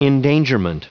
Prononciation du mot endangerment en anglais (fichier audio)
Prononciation du mot : endangerment